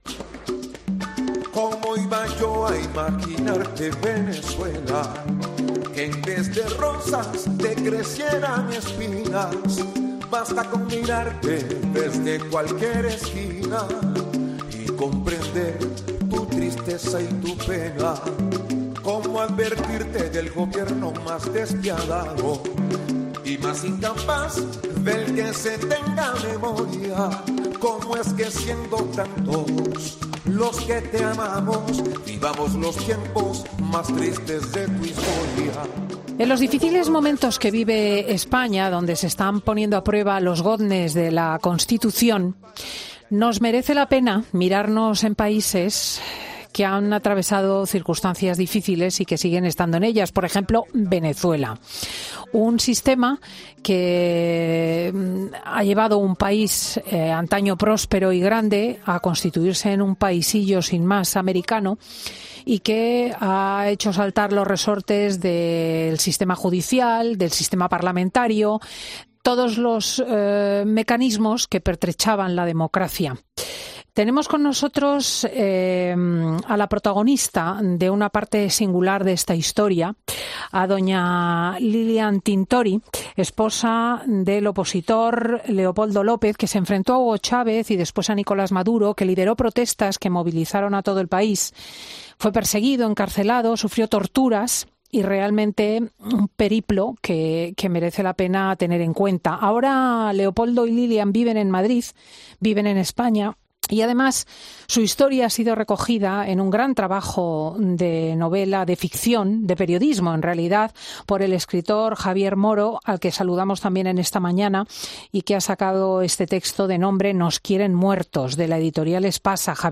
El político venezolano explicaba en COPE el proceso de primarias que tienen previsto en octubre y lo que hace el gobierno para evitarlo